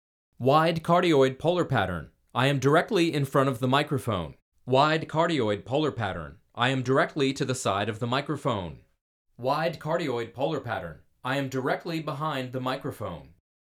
SUBCARDIOID OR WIDE CARDIOID
vocals-widecardioid-waveinformer.mp3